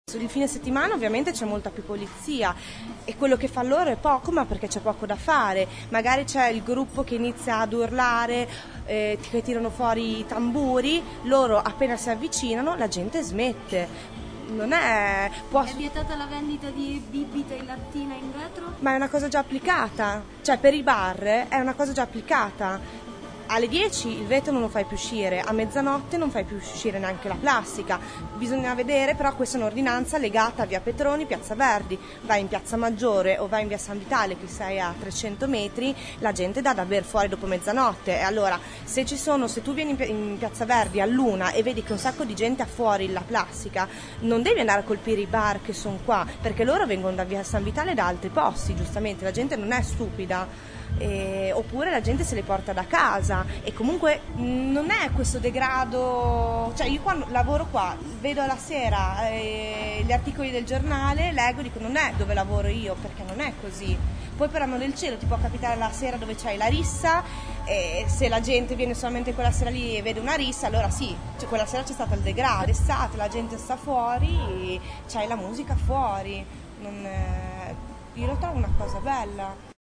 Da un giro in piazza, registratore alla mano, e due chiacchiere con chi la piazza la vive ma anche con chi in piazza ci vive, sono emersi i diversi punti di vista che alimentano il dibattito.